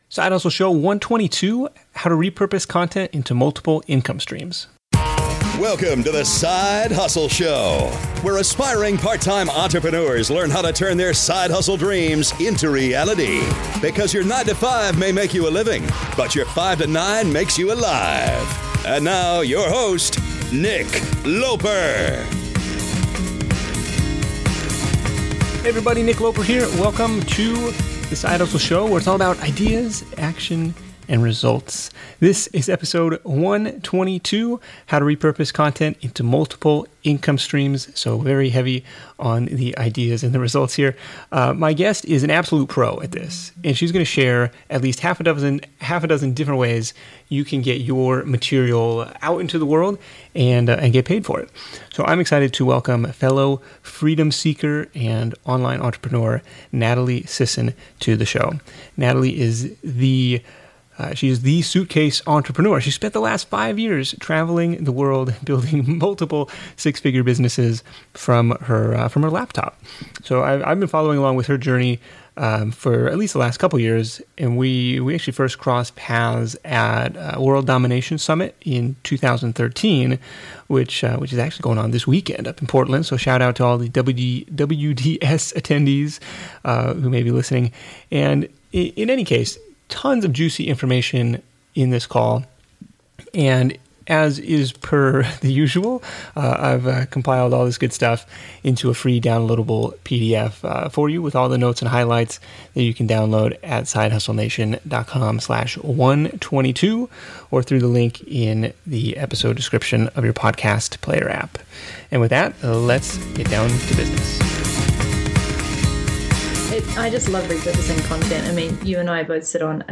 This week, I'm joined by fellow online entrepreneur and freedom-seeker